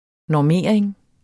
Udtale [ nɒˈmeˀɐ̯eŋ ]